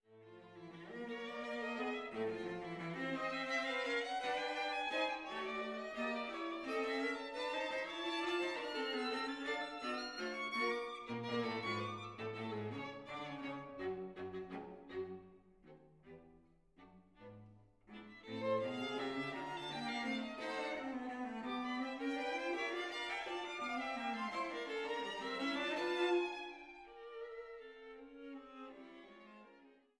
Violine
Viola
Cello